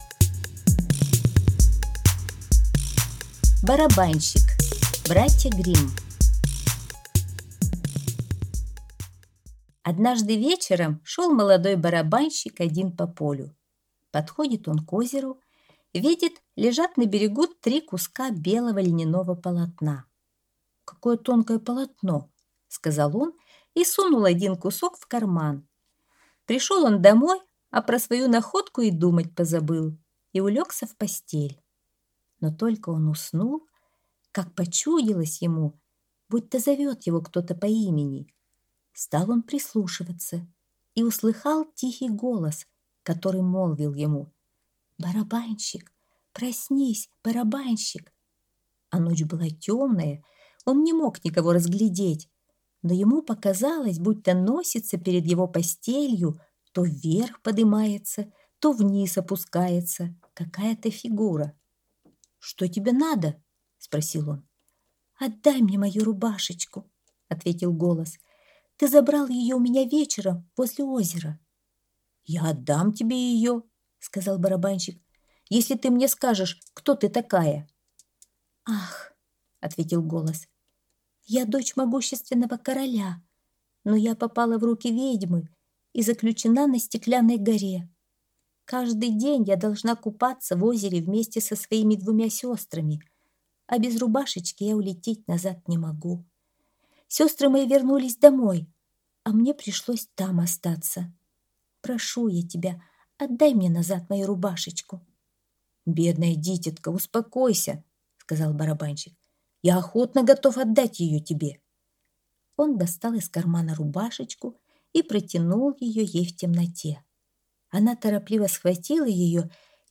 Барабанщик - аудиосказка Братьев Гримм - слушать онлайн